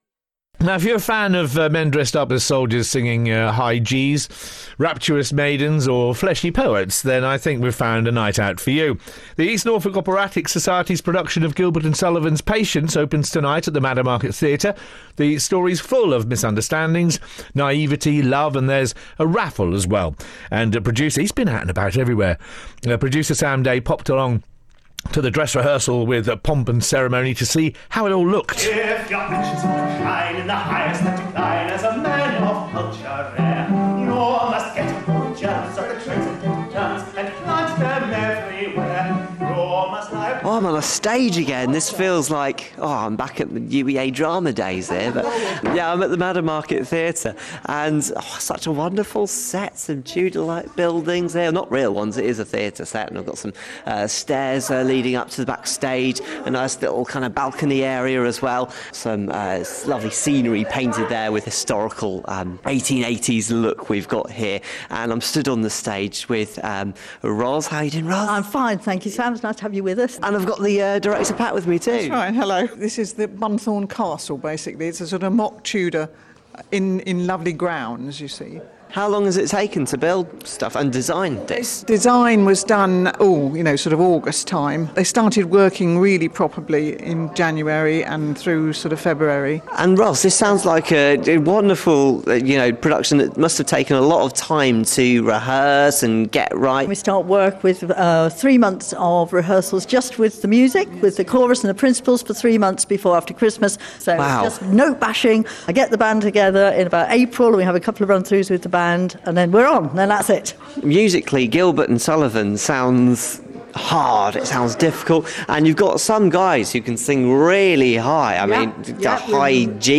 Publicity Interview broadcast on BBC Radio Norfolk on 9 May 2018